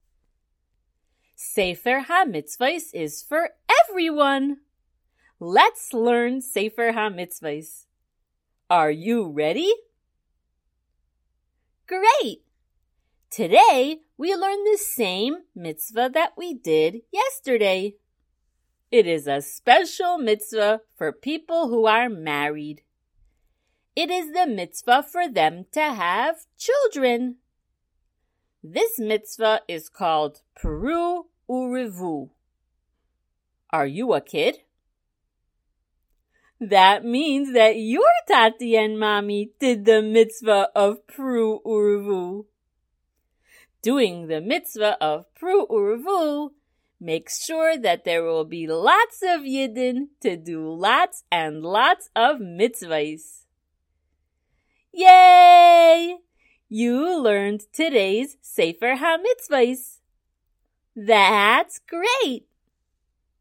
Listen to the shiur while you color!